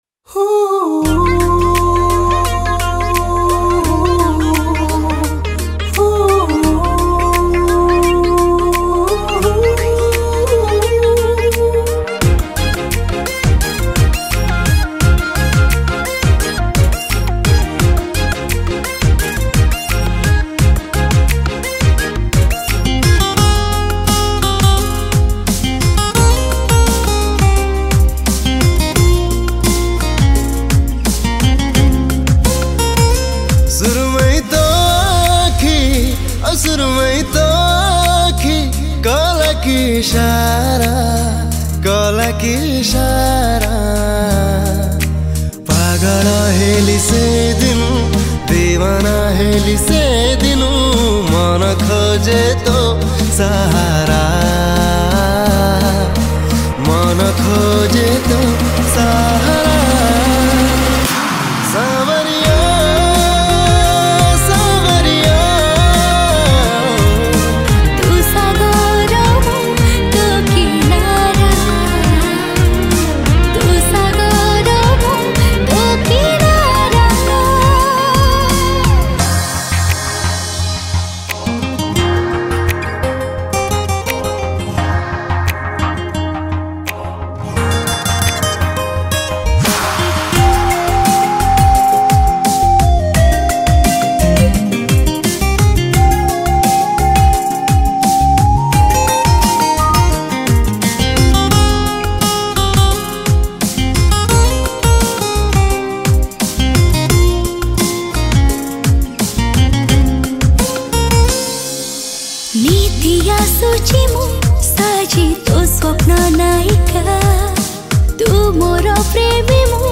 New Odia Romantic Song